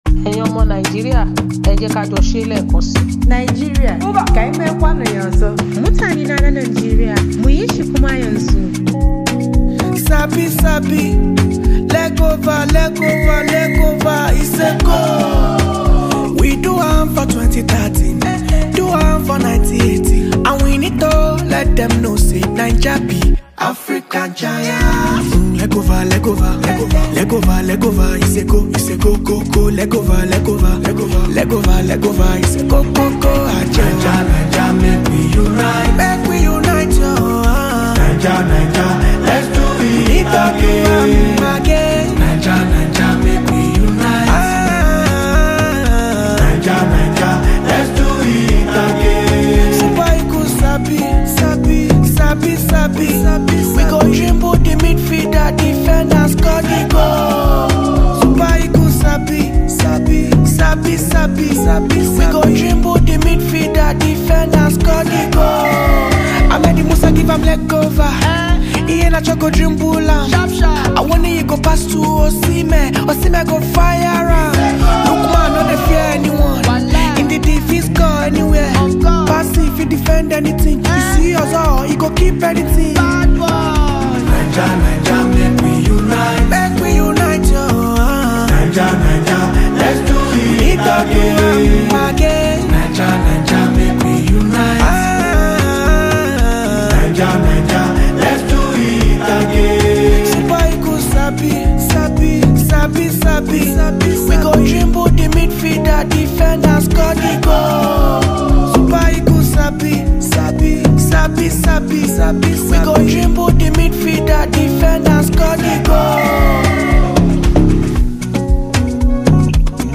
patriotic song